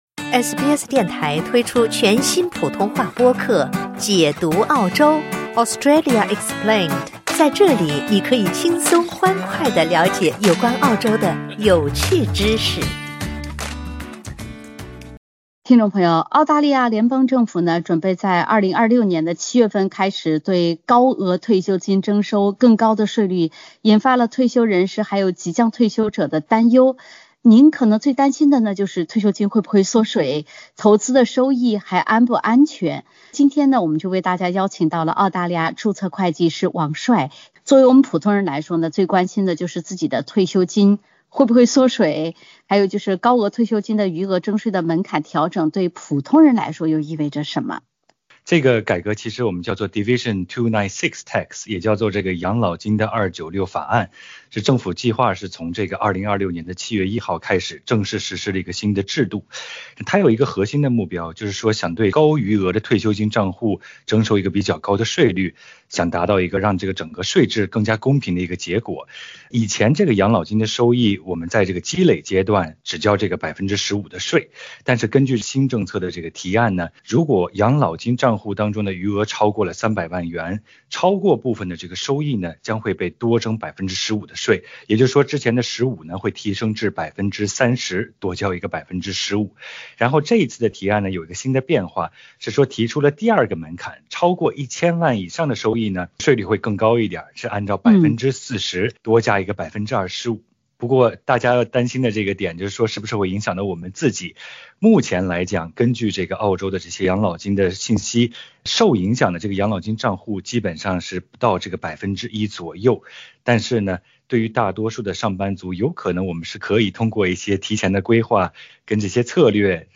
2026年退休金将被加税？注册会计师教你提前规划养老，合理避税，保障退休生活。